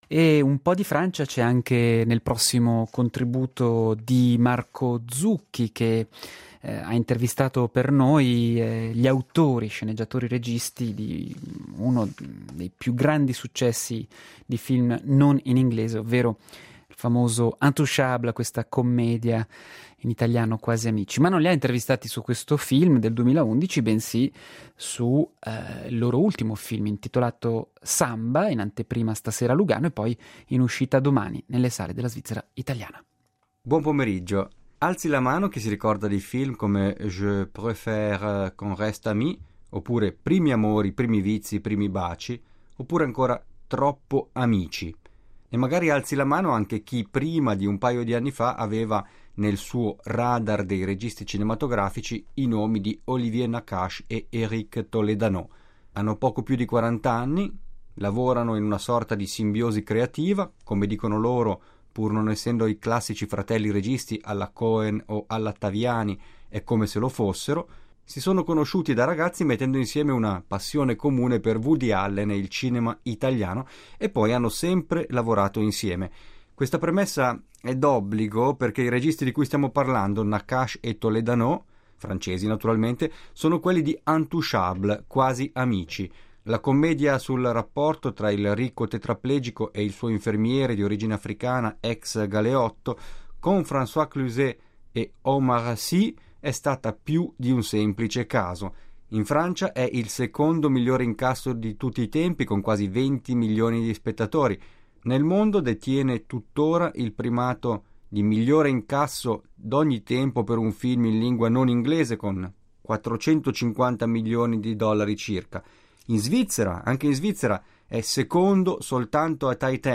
"SAMBA", intervista ai registi e sceneggiatori Olivier Nakache e Eric Toledano, i fortunati autori del film non in glese più visto al mondo, ovvero Quasi Amici (Intouchables)